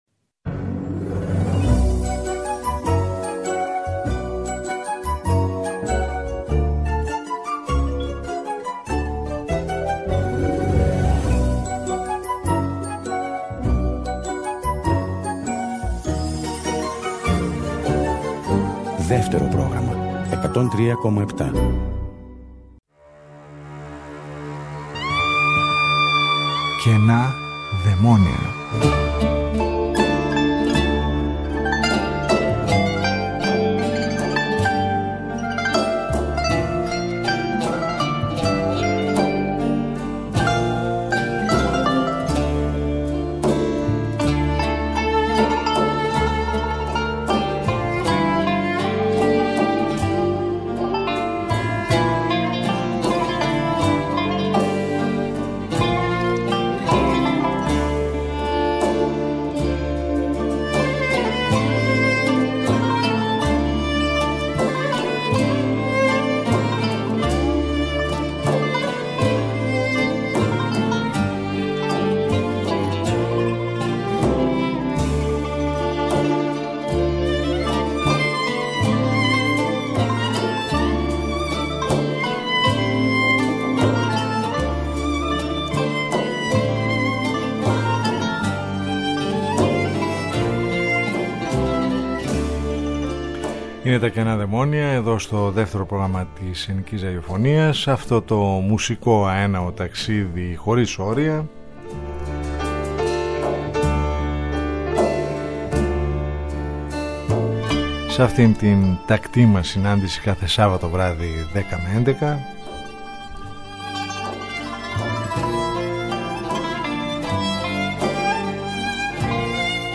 “ Καινά Δαιμόνια ” – Μια ραδιοφωνική συνάντηση κάθε Σάββατο στις 22:00 στο Δεύτερο Πρόγραμμα, που μας οδηγεί μέσα από τους ήχους της ελληνικής δισκογραφίας του χθες και του σήμερα σε ένα αέναο μουσικό ταξίδι.